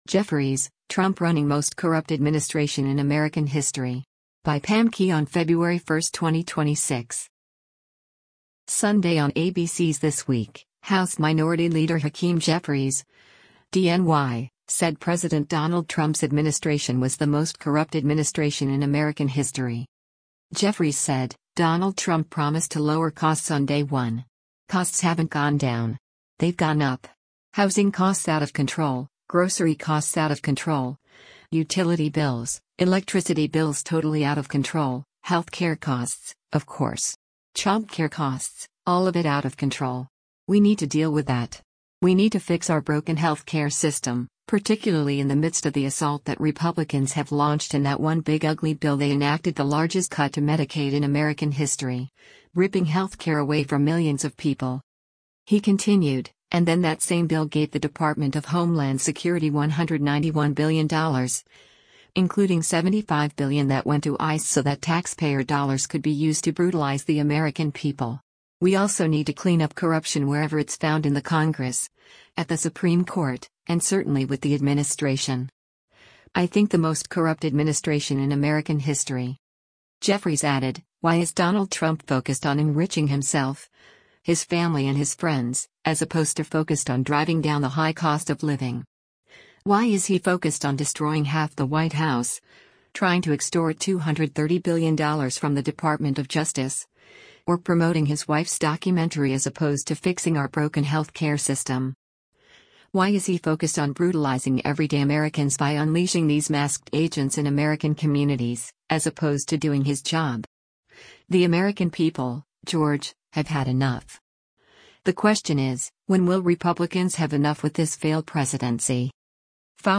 Sunday on ABC’s “This Week,” House Minority Leader Hakeem Jeffries (D-NY) said President Donald Trump’s administration was “the most corrupt administration in American history.”